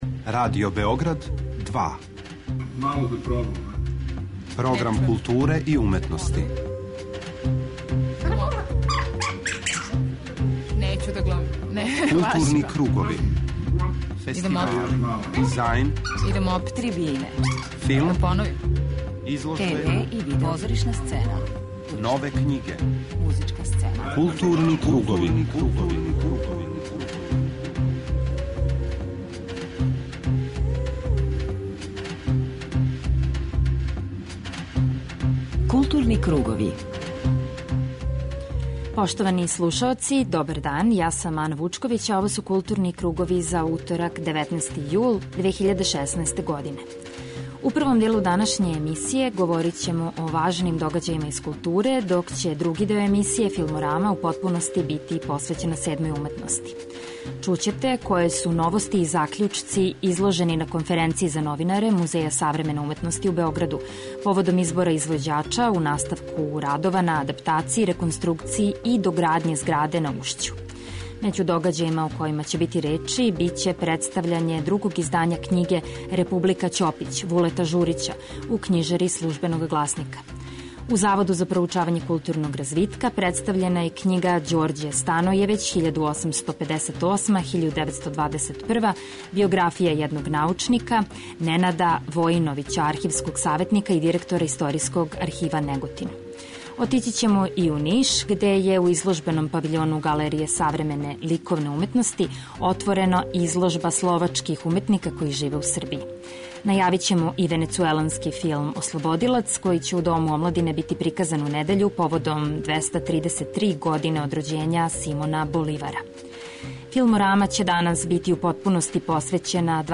Почетак темата резвервисан је за разговоре са овогодишњим добитницима Награде Александар Лифка, аустријским редитељем Урлихом Зајдлом и српским редитељем Слободаном Шијаном, представницима особених али рaзличитих ауторских светова, који говоре о свом начину рада, положају уметника у данашњем времену, о својим будућим пројектима.